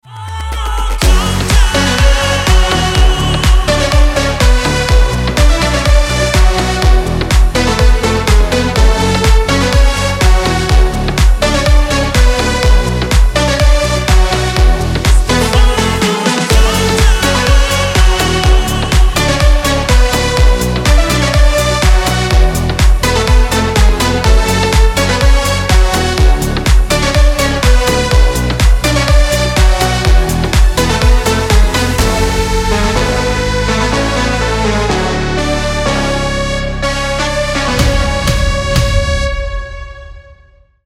Ремикс топового хита